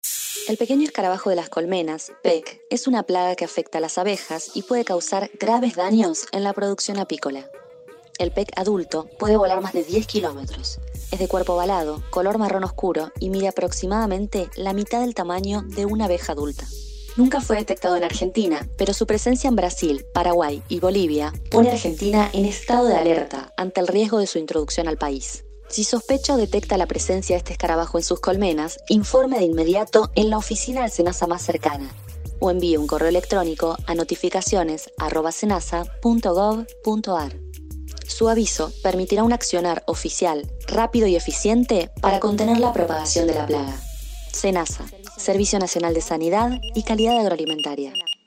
Este spot radial está disponible, para descargar y compartir.